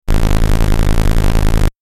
دانلود آهنگ مسابقه 1 از افکت صوتی حمل و نقل
جلوه های صوتی
برچسب: دانلود آهنگ های افکت صوتی حمل و نقل دانلود آلبوم صدای مسابقه ماشین از افکت صوتی حمل و نقل